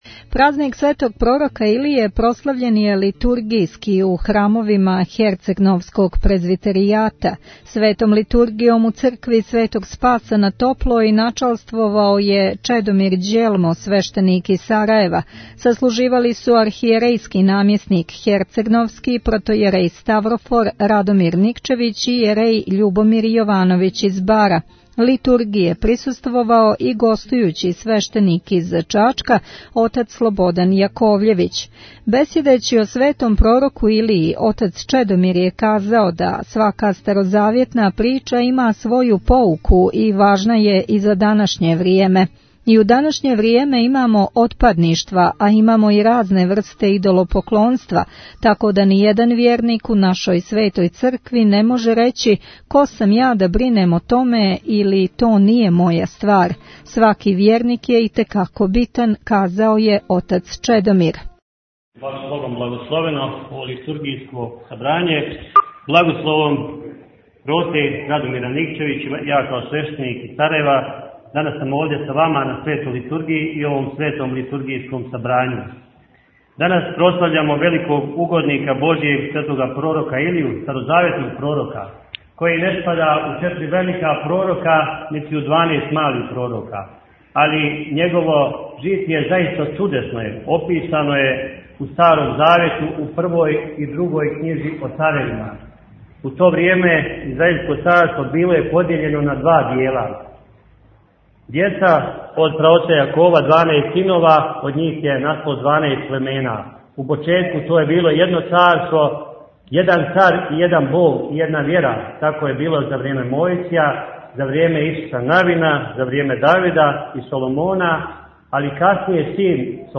Илинданско литургијско сабрање на Топлој Tagged: Извјештаји 6:53 минута (1.18 МБ) Празник Светог пророка Илије прослављен је литургијски у храмовима херцегновског протопрезвитеријата.